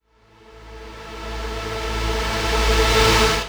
VEC3 Reverse FX
VEC3 FX Reverse 36.wav